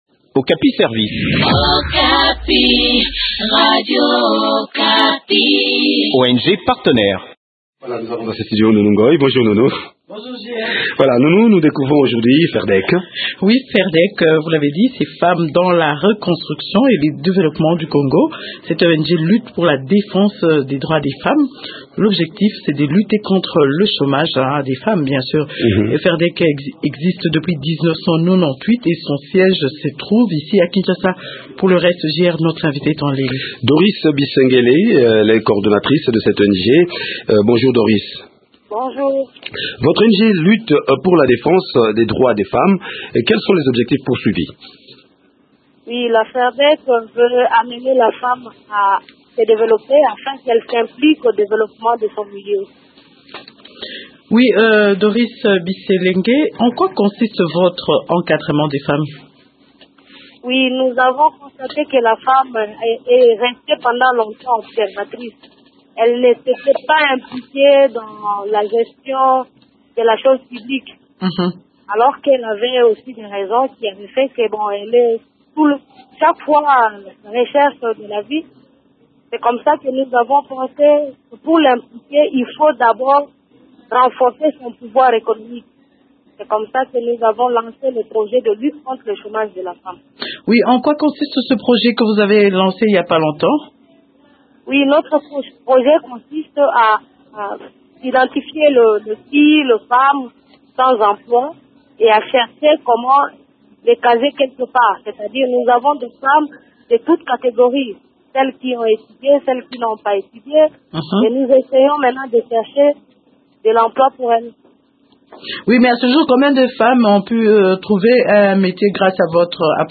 Découvrons les activités de cette structure dans cet entretien